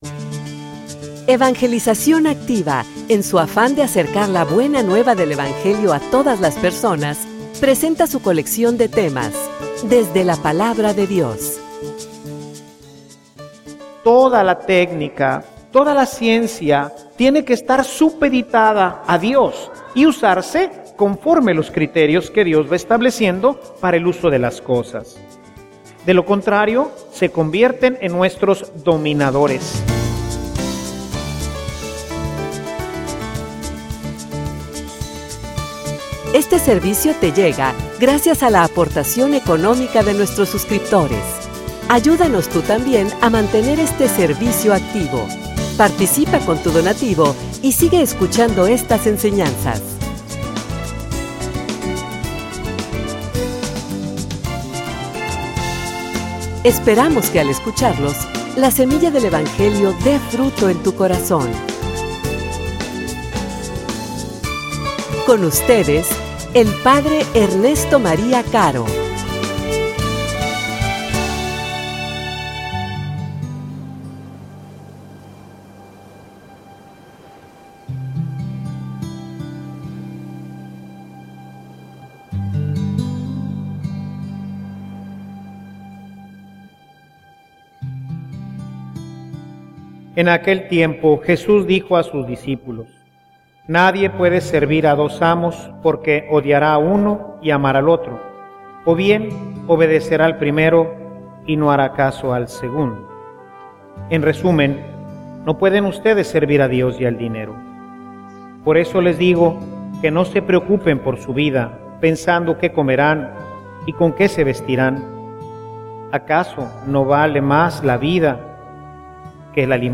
homilia_Confia_en_el_Senor.mp3